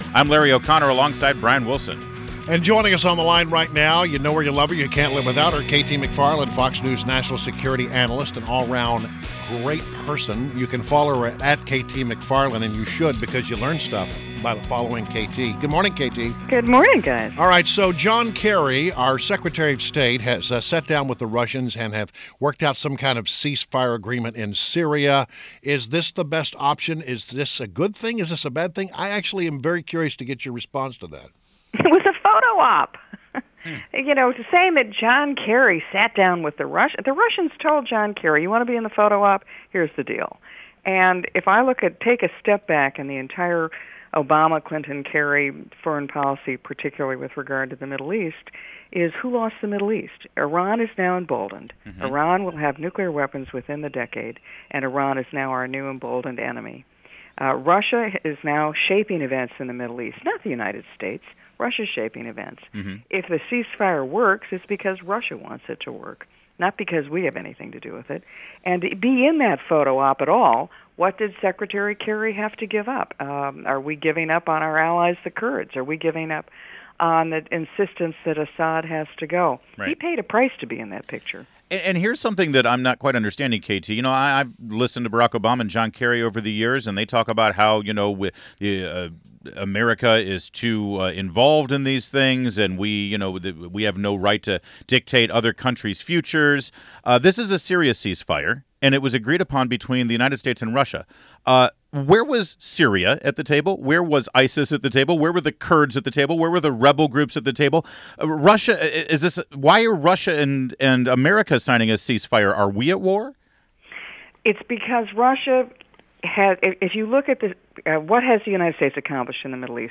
WMAL Interview - KT MCFARLAND - 09.14.16